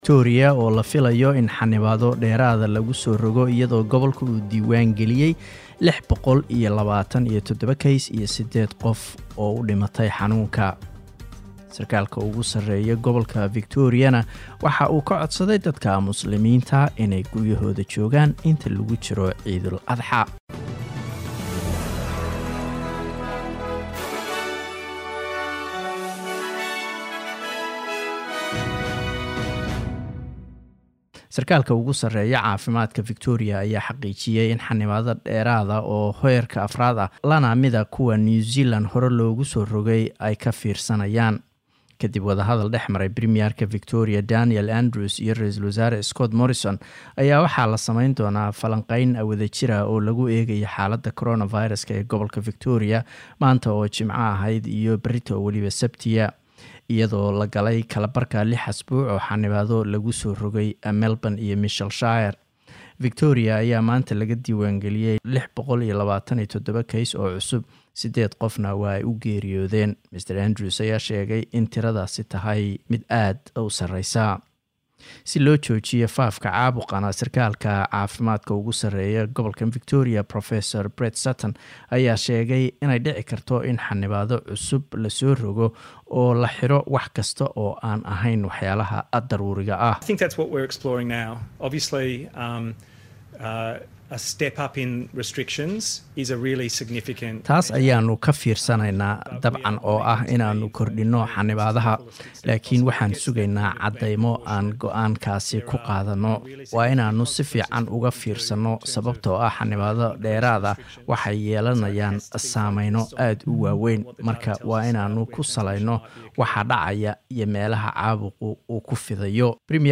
sbs_somali_news_31_july_online.mp3